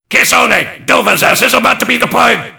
mvm_bomb_alerts13.mp3